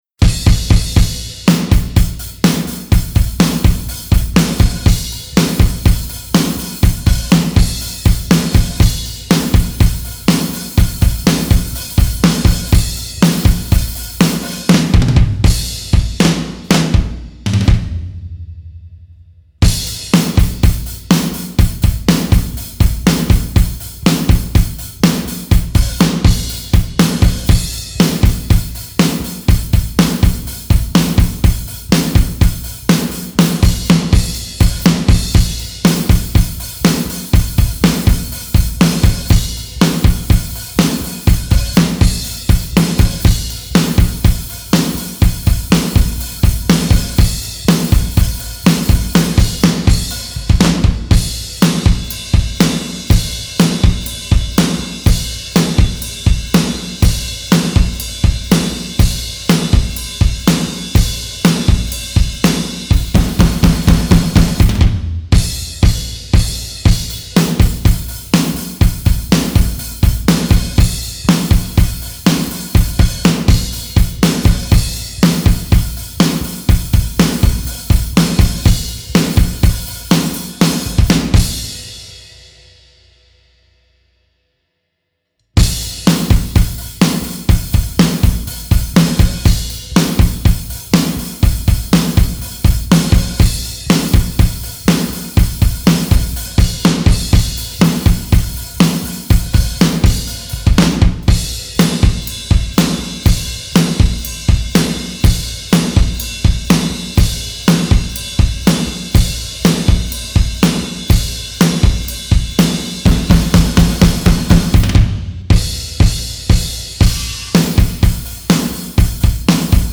I track drums from start to finish without fixes.
Just Drums